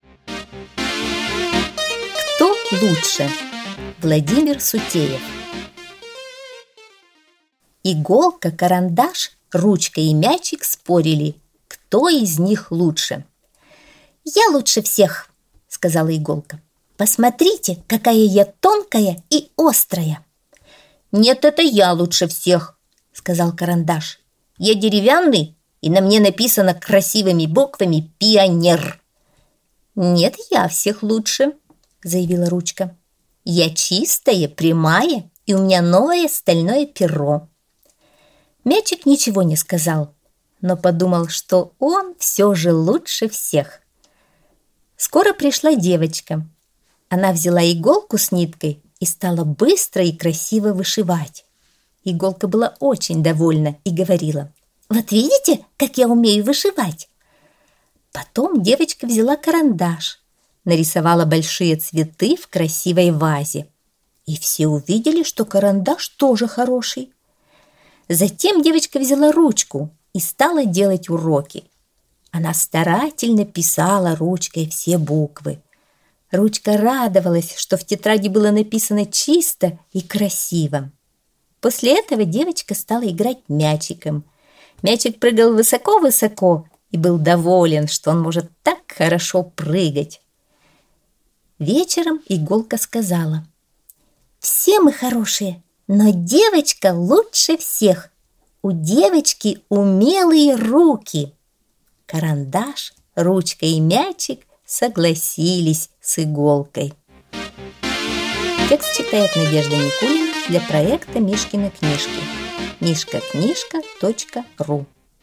Аудиосказка «Кто лучше?»